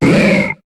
Cri de Tadmorv dans Pokémon HOME.